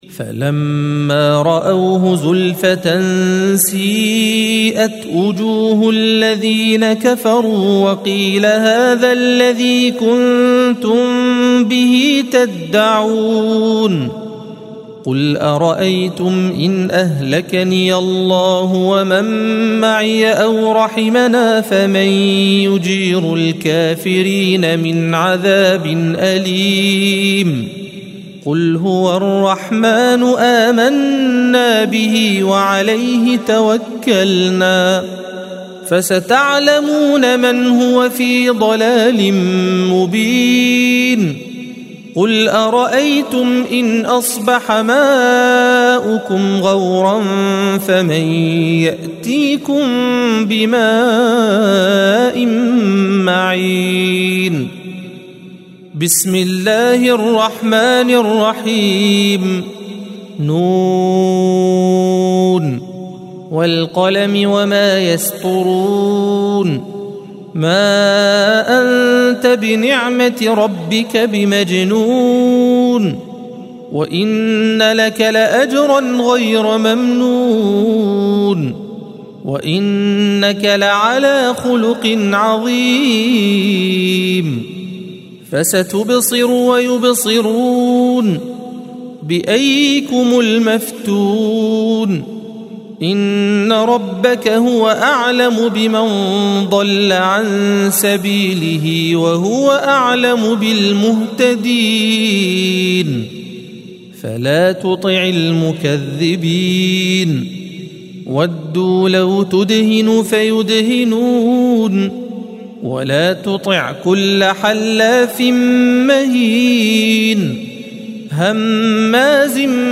الصفحة 564 - القارئ